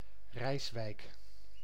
Rijswijk (Dutch: [ˈrɛisʋɛik]
Nl-Rijswijk.ogg.mp3